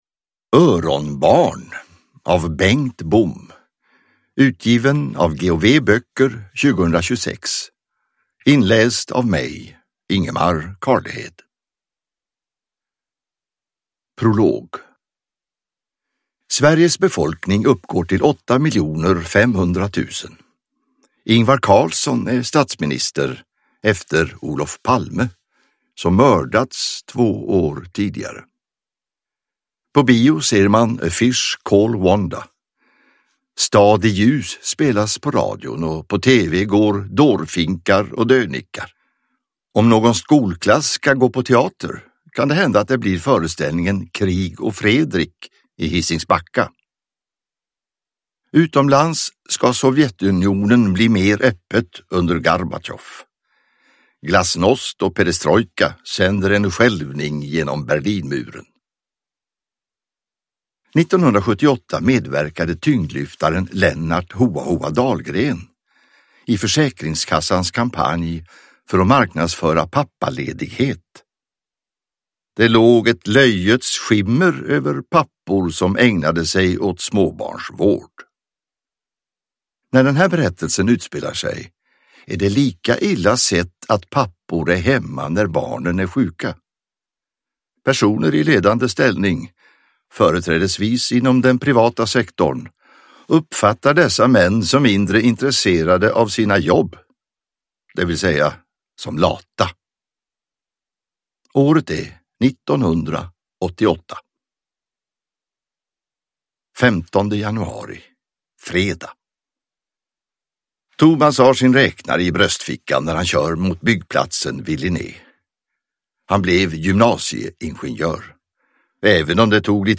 Öronbarn – Ljudbok